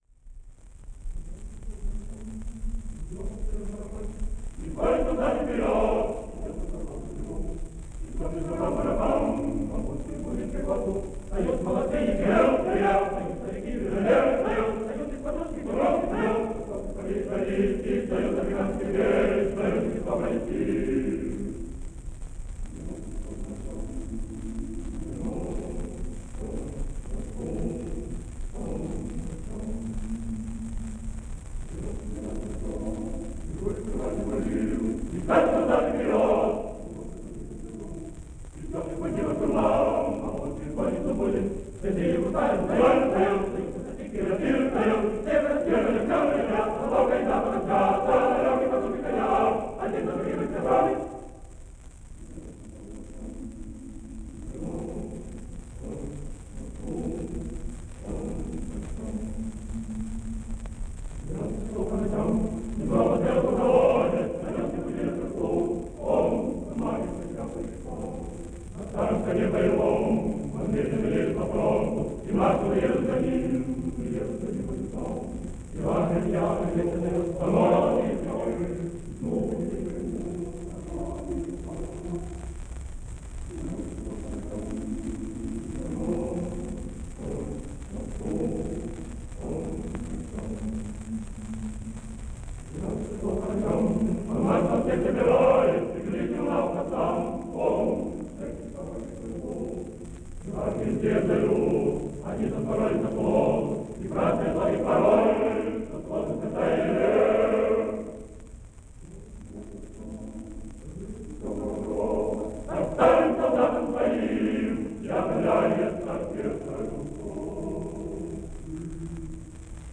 The voices are young and fresh.